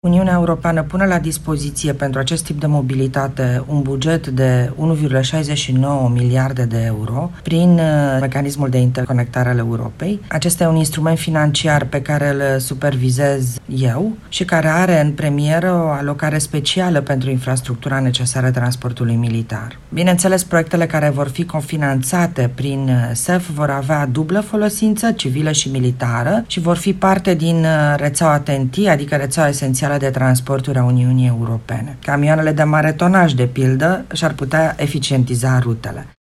Ccomisarul european pentru Transporturi, Adina Vălean, mai spune, într-o declarație acordată Europa FM, că România poate primi prin fondul de mobilitate militară până la 85% din banii necesari proiectelor de infrastructură critică.